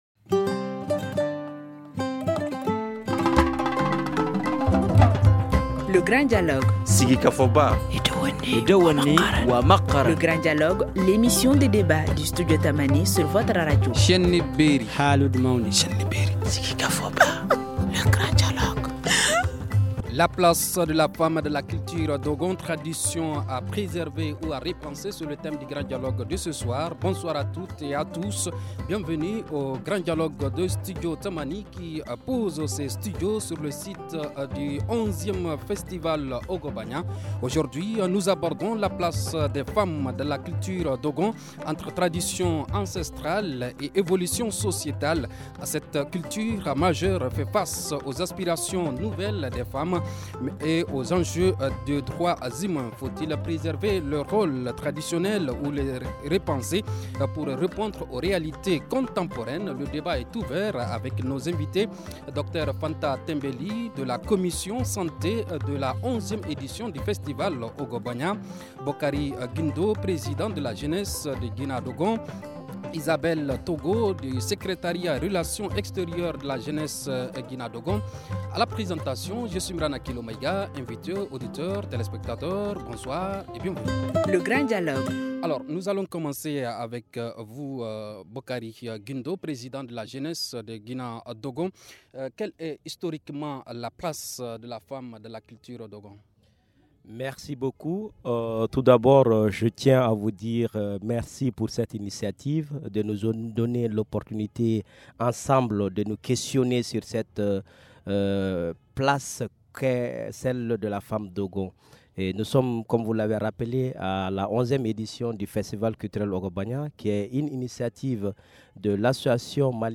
Bonsoir à toutes et à tous, bienvenue au Grand Dialogue de Studio Tamani qui pose ses studios sur le site du 11e festival Ogobagna.